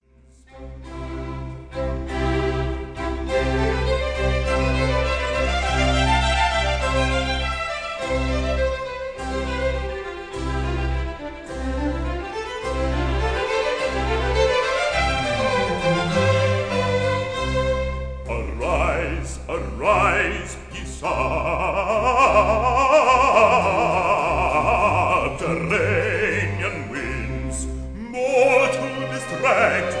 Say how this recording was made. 1958 stereo recording